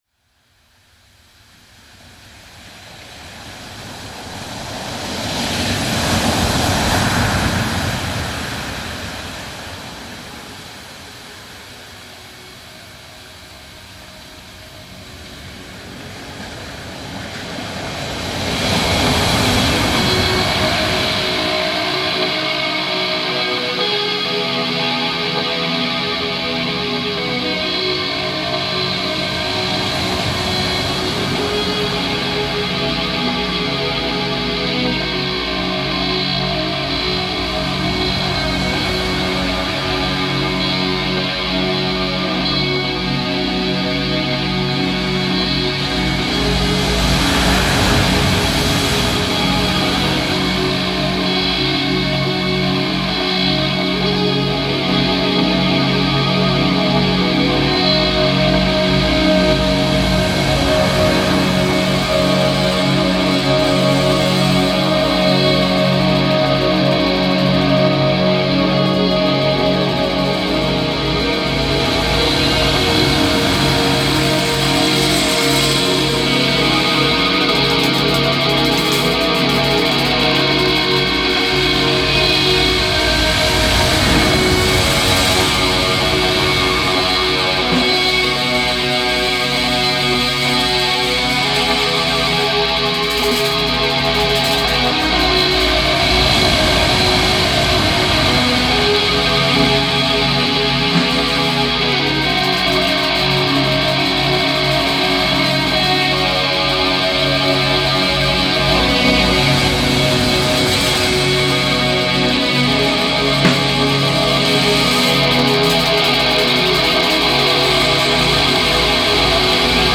have grown into first rate indie rockers.